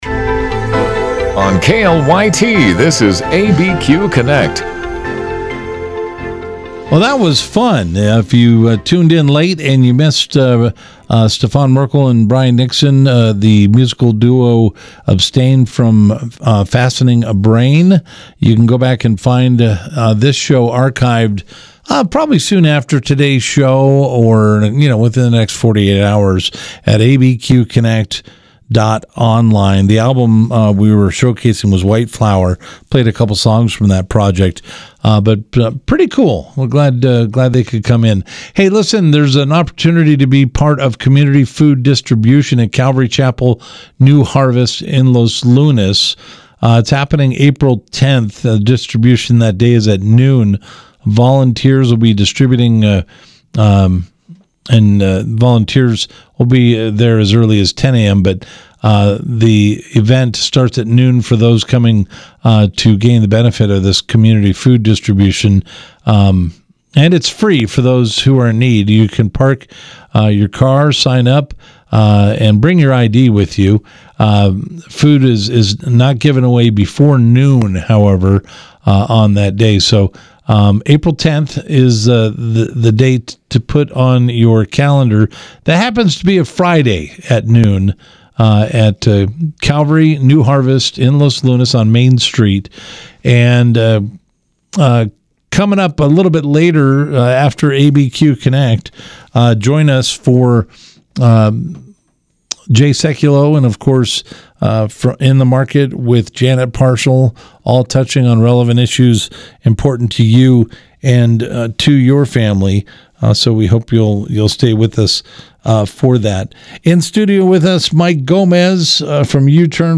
Live, local and focused on the New Mexico area.